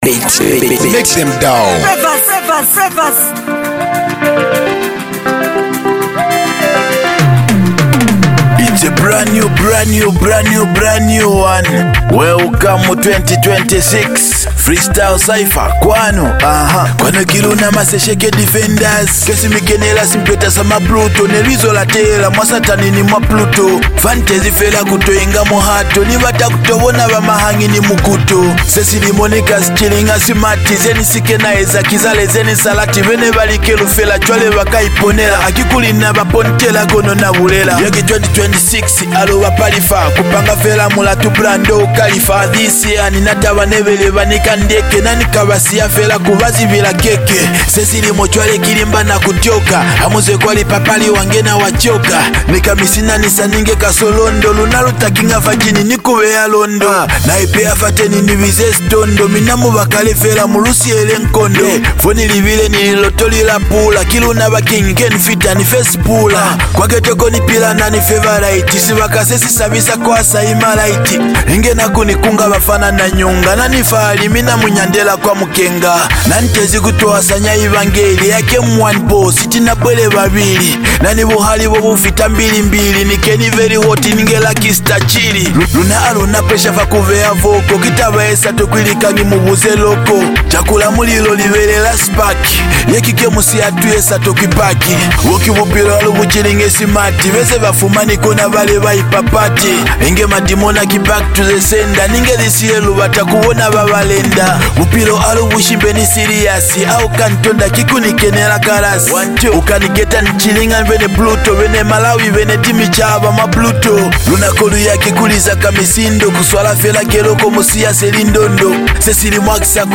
vibrate sound